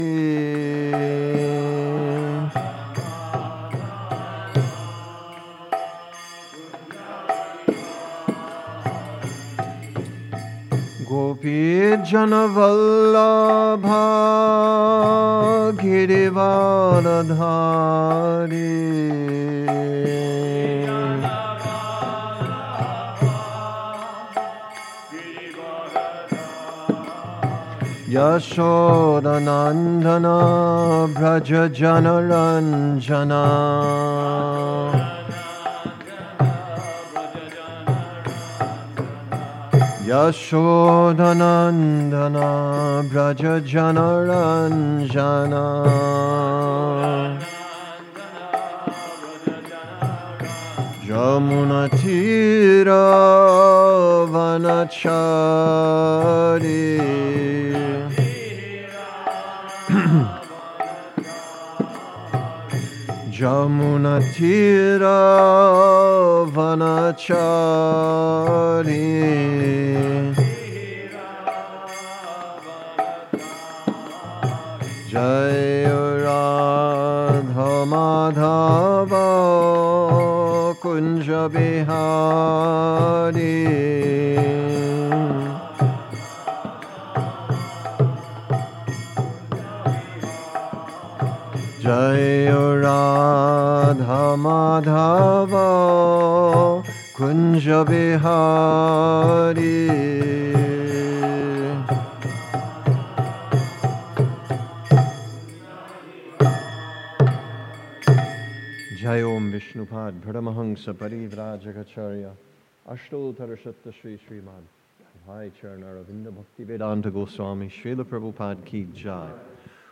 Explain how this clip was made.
2025 at the Hare Krishna temple in Alachua, Florida. The Srimad-Bhagavatam is a sacred Vaishnava text from India that narrates the history of God (Krishna) and His devotees.